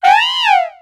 Cri de Léopardus dans Pokémon X et Y.